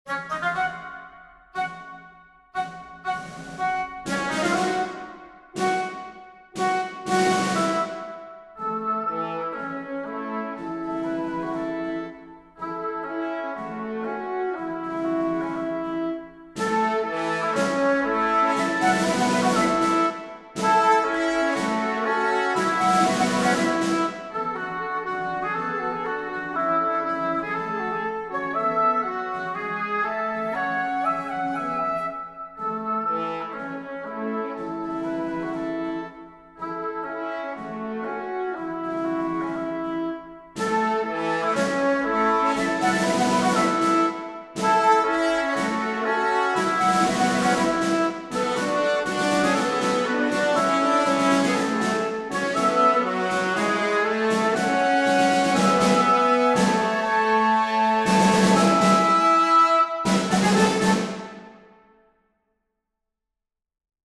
I'm realizing that I never learned how to recognize key signatures off of the flats/sharps, so I just shove the notes I want into C Major LOL Music theory be wildin' This is a basic bitch march. 2/4 time, simple melody with a repeat, snare.